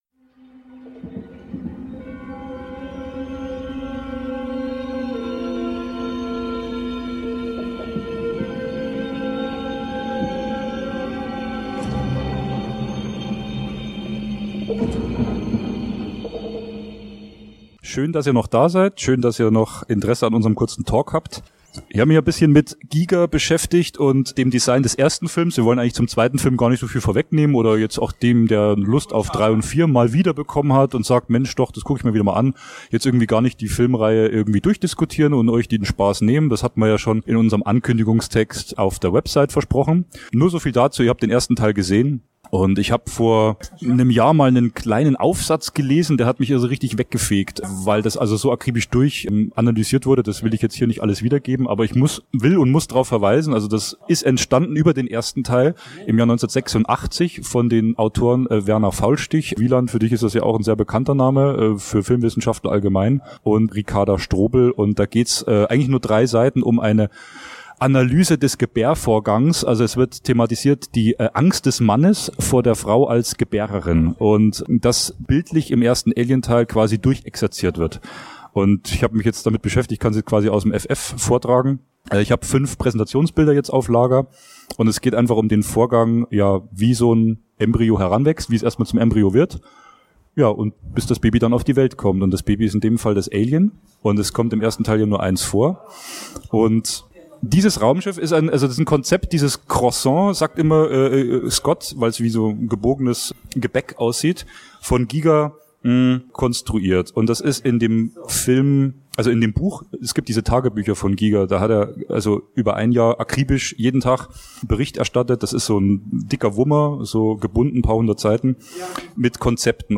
LW - Panel zur Alien-Night ~ deepredradio Podcast